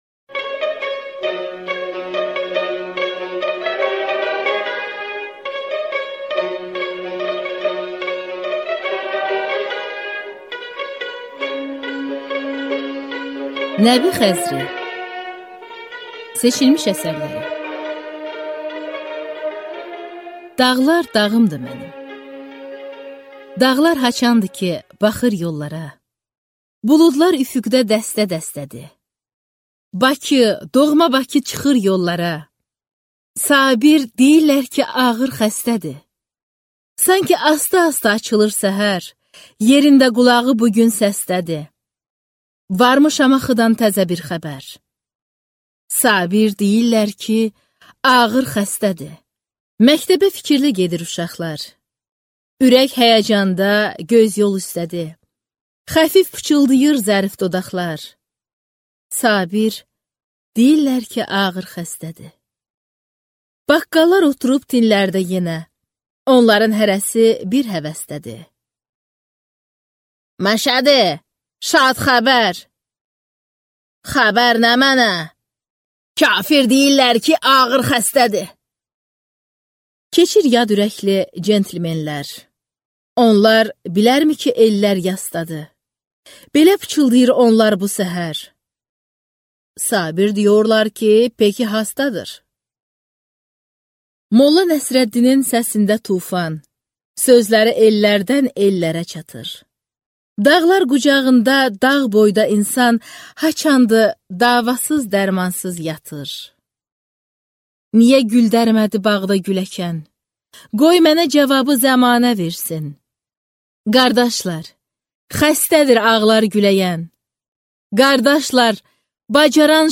Аудиокнига Seçilmiş əsərlər - Nəbi Xəzri | Библиотека аудиокниг